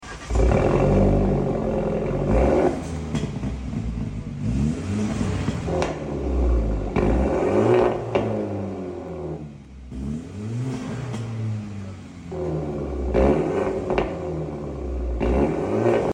Bmw G20 catback system valvetronic sound effects free download
Bmw G20 catback system valvetronic exhaust sound ..